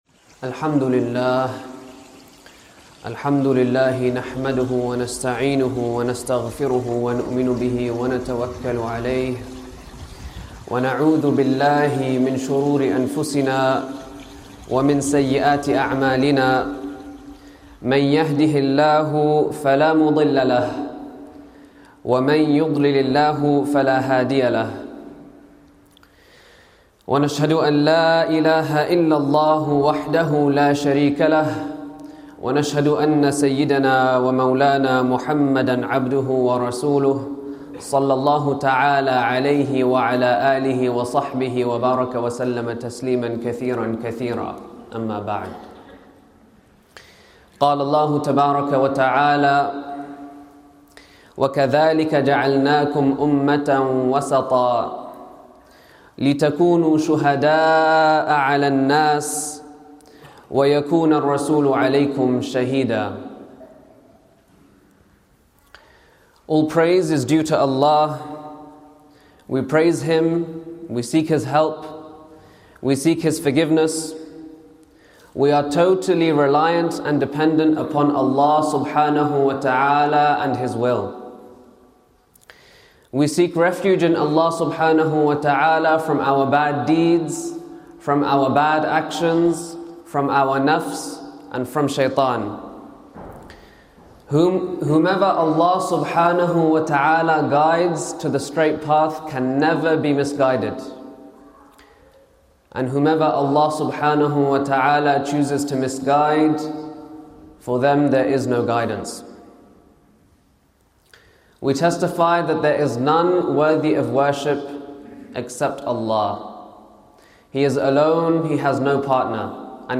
Cambridge Central Mosque/Being in Balance
Friday Sermon.mp3